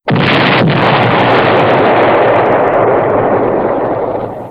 mvm_tank_explode.wav